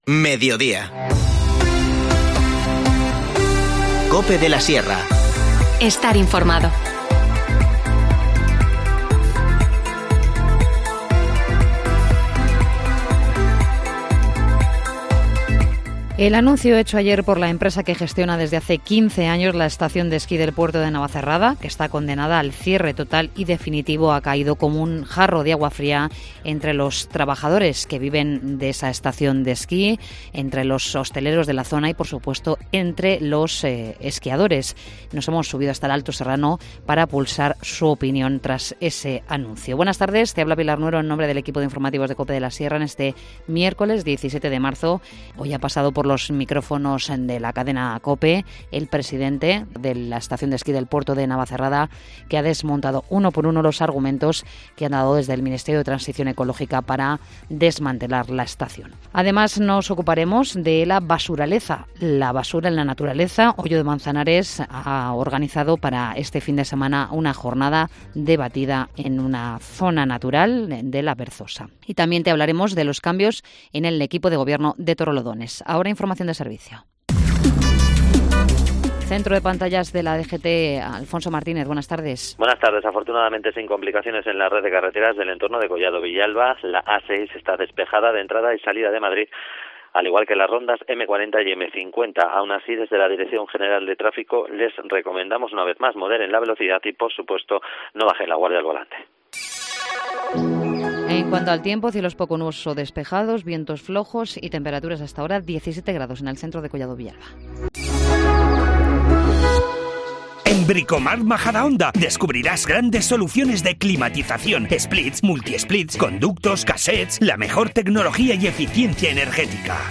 Informativo Mediodía 17 marzo